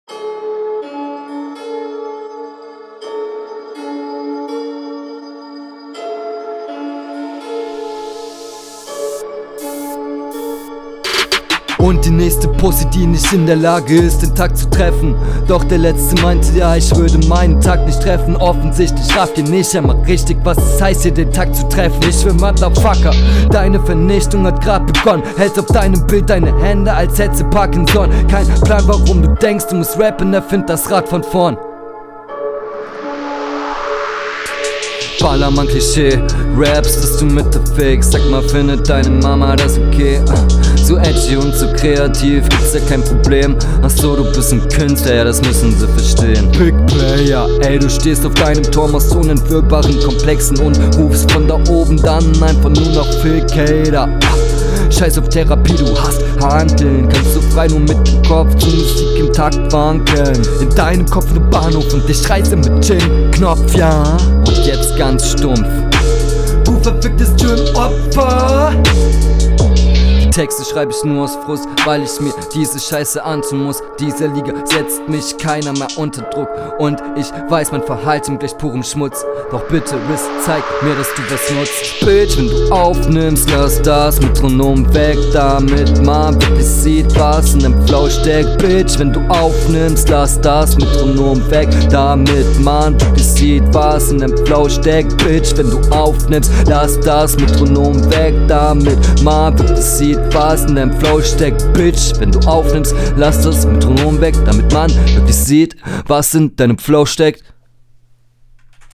Beat sagt dir hier mehr zu.
Klingt wie´n Freestyle, ein nicht-punchender Freestyle.